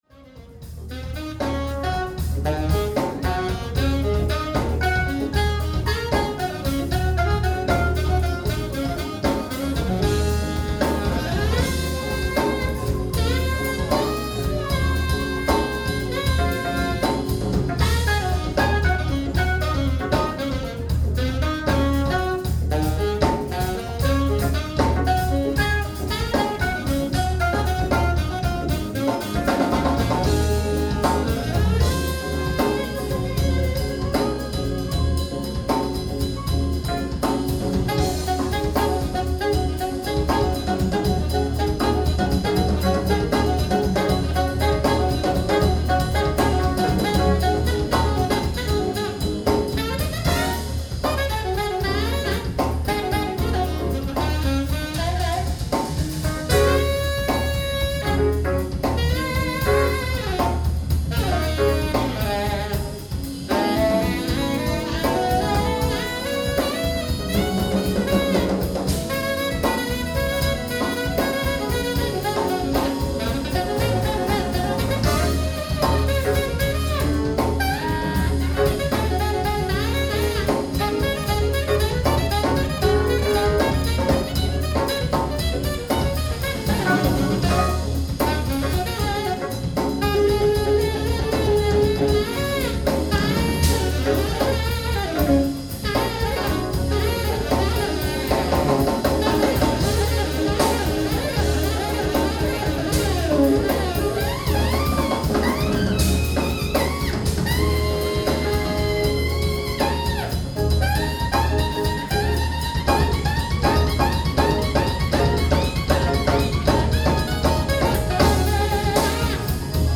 ライブ・アット・ブルー・ノート、ミラノ 03/21/2014
※試聴用に実際より音質を落としています。